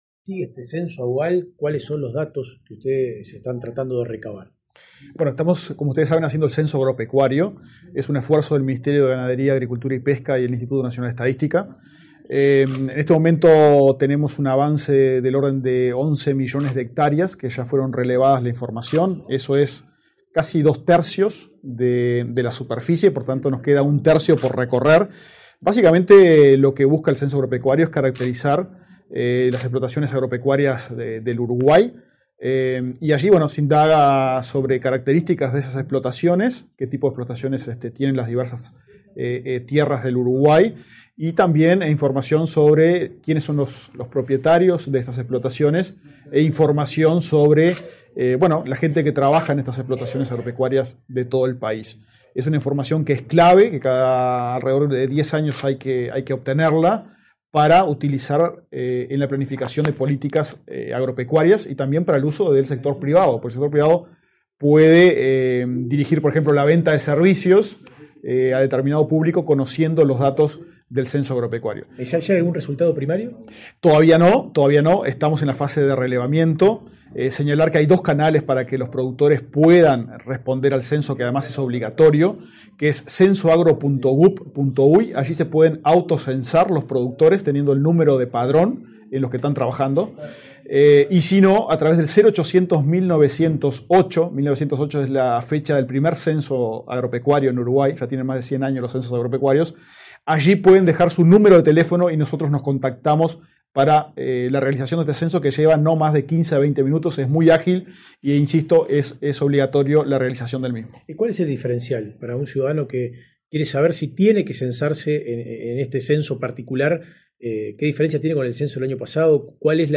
Declaraciones a la prensa del director del INE, Diego Aboal
Declaraciones a la prensa del director del INE, Diego Aboal 18/06/2024 Compartir Facebook X Copiar enlace WhatsApp LinkedIn Tras participar en la presentación de los datos y avances del Censo General Agropecuario, este 18 de junio, el director del Instituto Nacional de Estadística (INE), Diego Aboal, realizó declaraciones a la prensa.